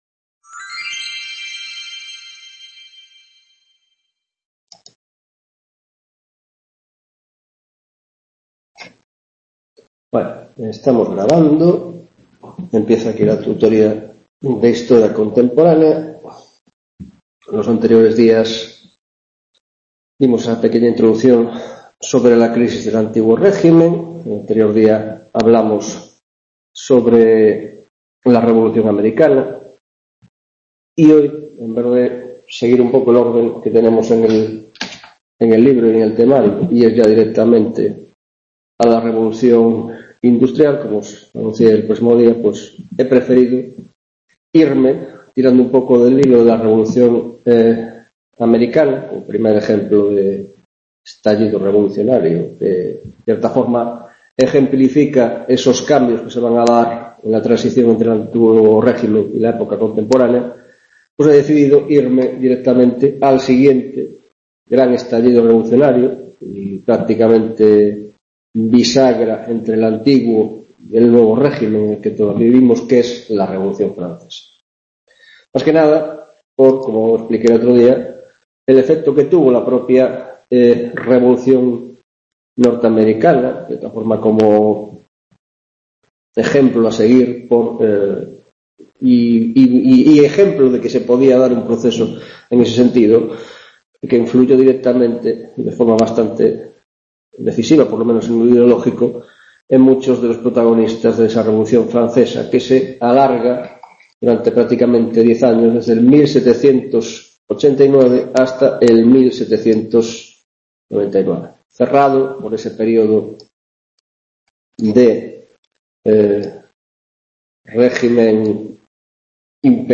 3ª tutoria de Historia Contemporánea - Revolución Francesa